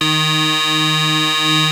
OSCAR 15 D#3.wav